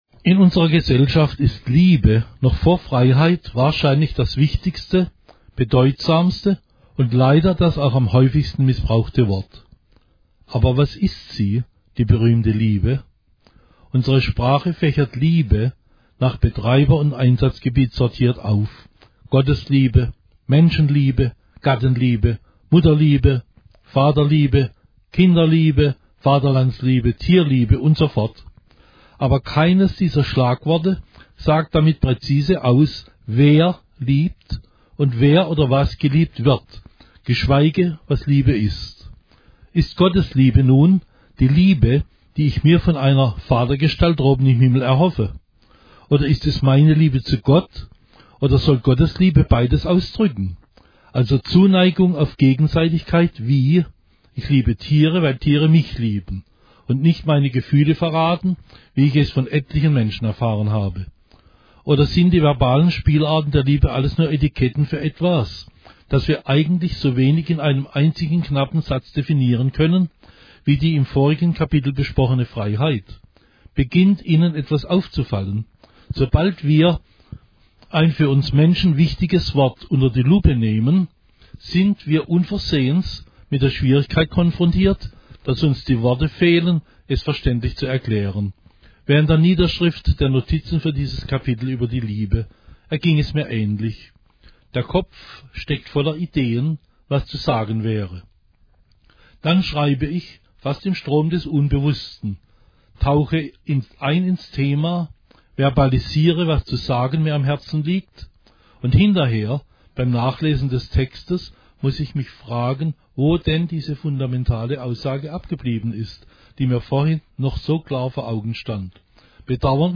che legge il Capitolo 9